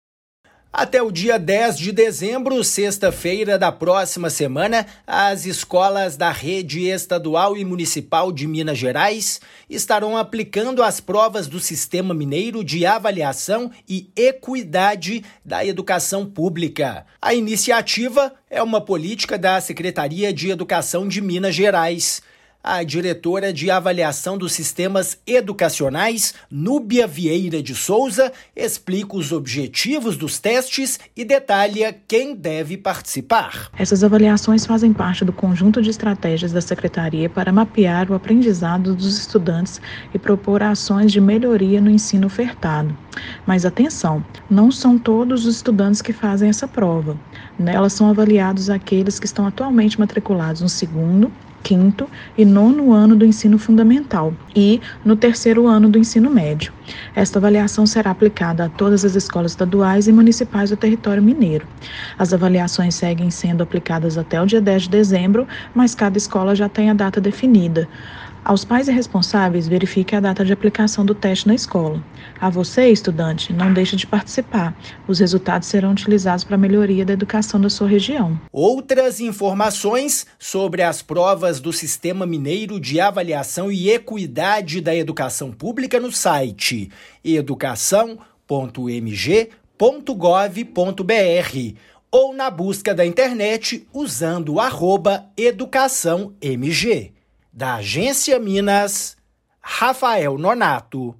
Participam da iniciativa escolas das redes estadual e municipais. Ouça a matéria de rádio.
MATÉRIA_RÁDIO_SIMAVE_EDUCAÇÃO.mp3